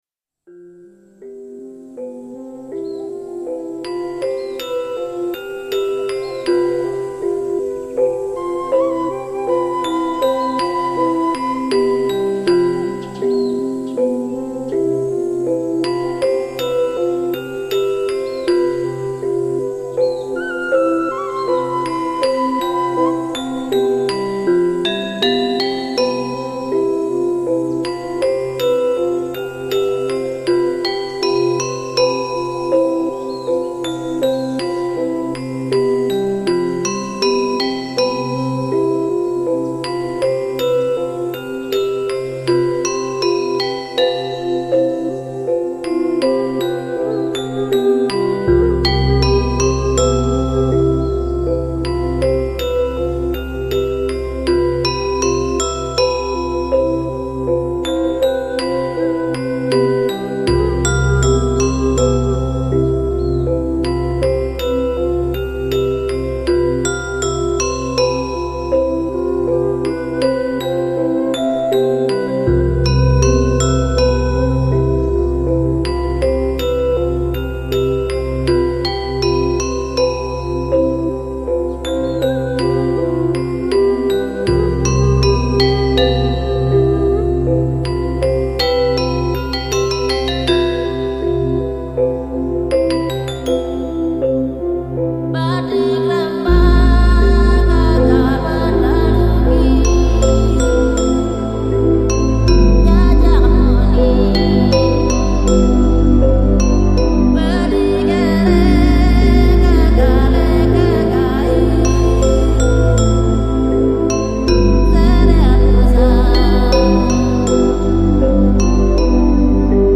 本YOGA系列共分两集，比前张专辑更多尝试新的曲风与节奏，是另一种放松的新体验。
这张CD收录了12首能使人感受到亚洲宁静的世界观在不断扩大，让人联想到涟漪声和海边树丛沙沙声的曲子。